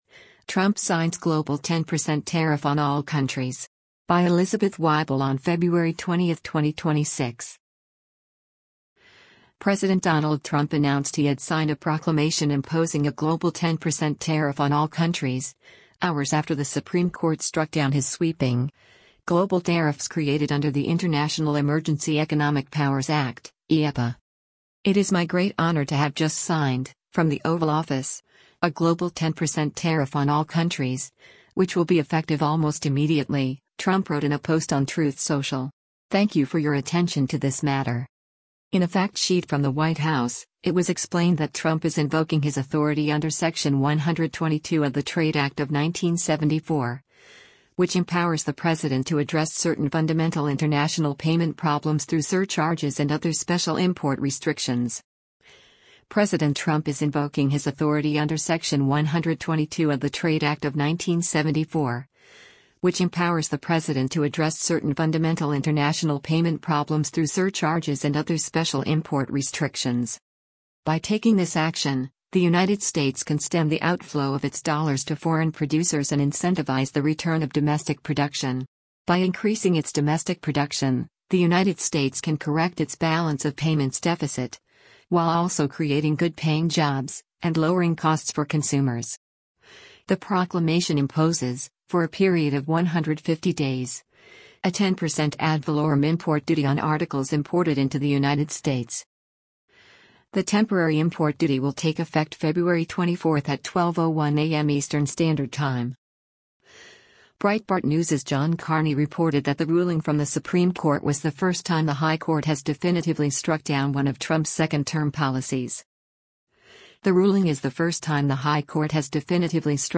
US President Donald Trump speaks during a press conference in the Brady Press Briefing Roo